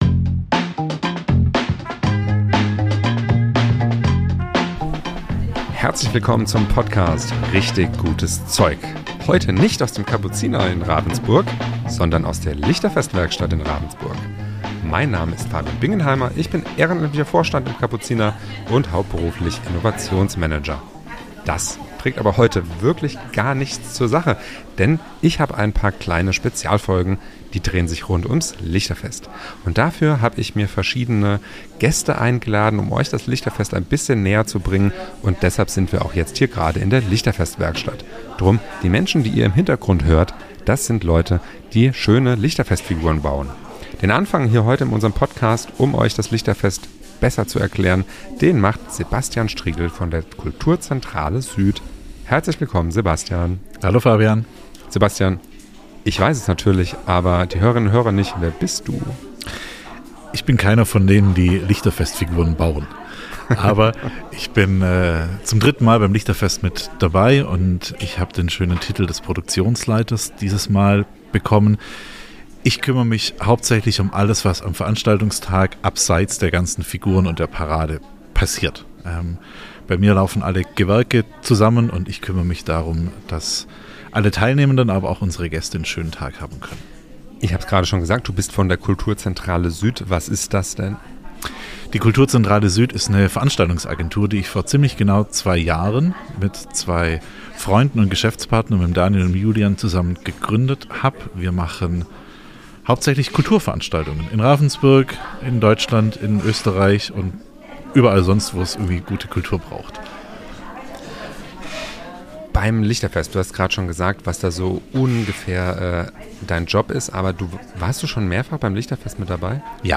Beschreibung vor 1 Monat Willkommen zur zweiten Spezialfolge rund ums Lichterfest Ravensburg 2026 – diesmal direkt aus der Lichterfest-Werkstatt.